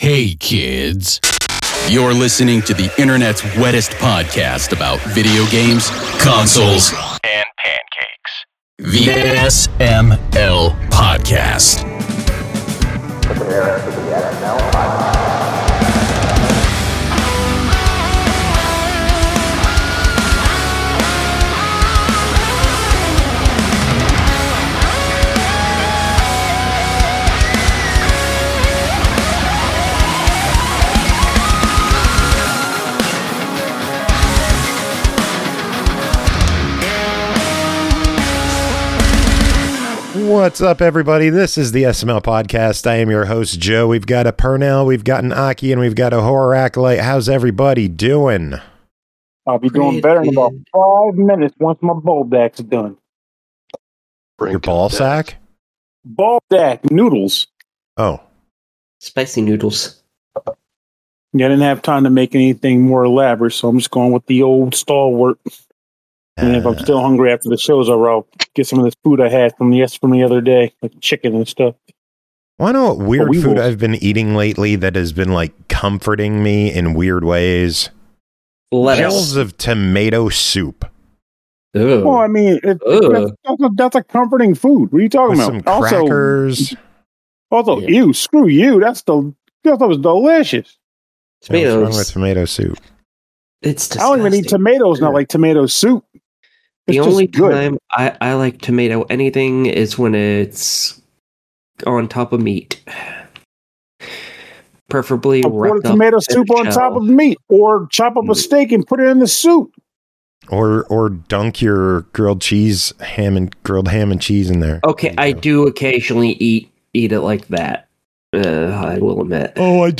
Some of us are sick, some are tired, and we got some reviews, so let’s talk games!